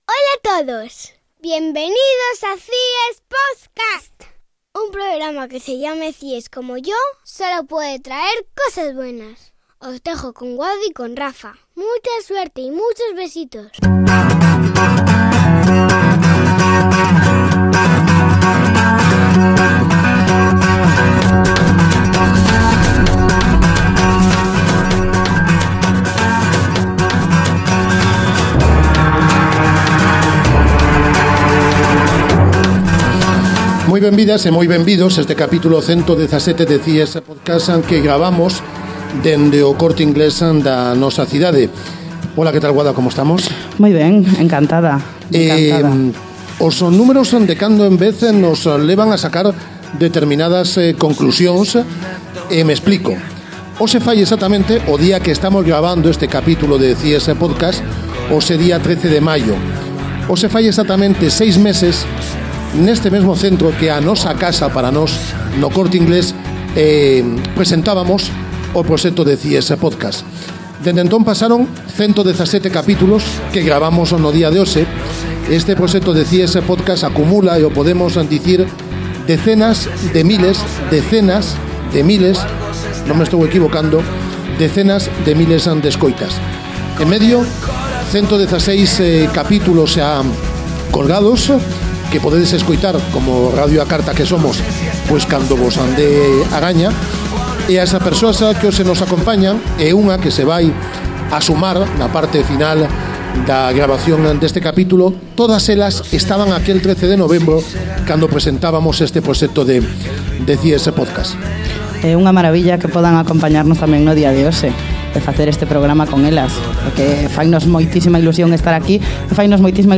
Capítulo especial das Letras Galegas, desde a libraría do Corte Inglés de Vigo